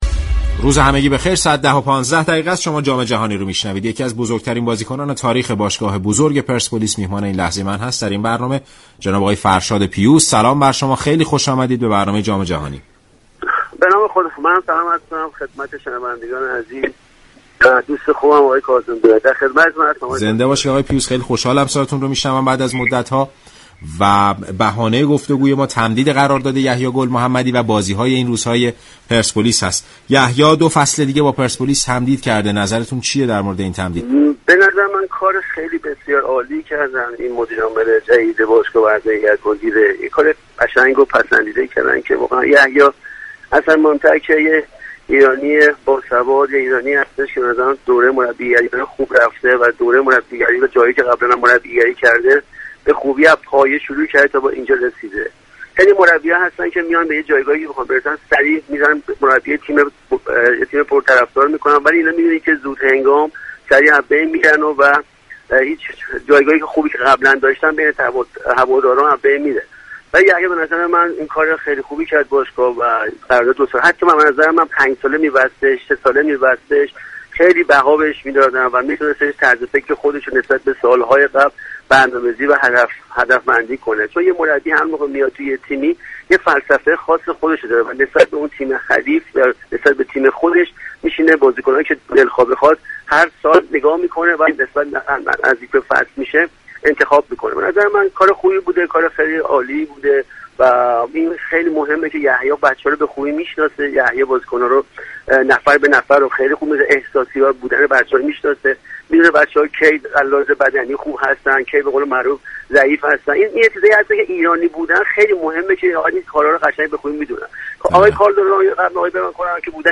به گزارش رادیو ورزش؛ فرشاد پیوس، پیشكسوت پرسپولیس، در خصوص تمدید قرارداد گل محمدی به برنامه "جام جهانی" رادیو ورزش گفت: به نظرم مدیرعامل جدید باشگاه پرسپولیس و اعضای هیئت مدیره كار بسیار خوبی انجام دادند.